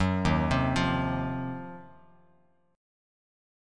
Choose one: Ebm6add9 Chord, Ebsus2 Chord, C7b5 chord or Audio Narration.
Ebm6add9 Chord